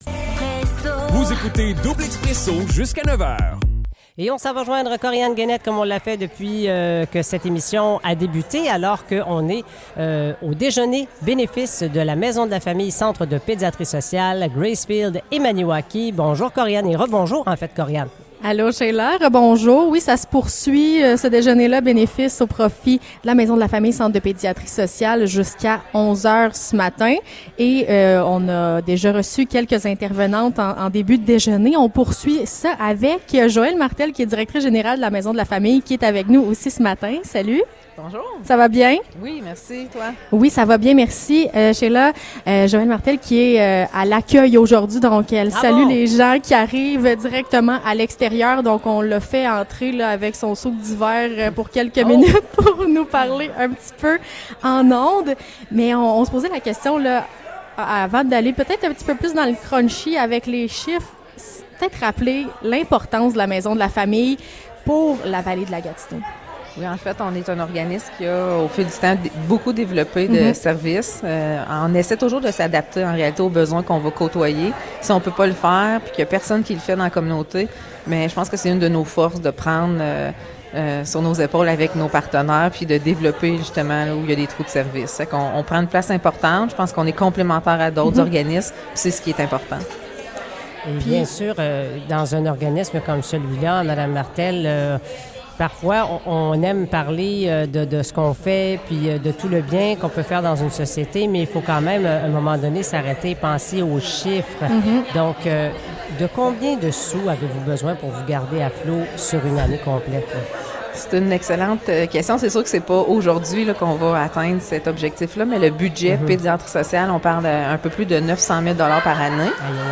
Déjeuner de la Maison de la famille 2023 : entrevue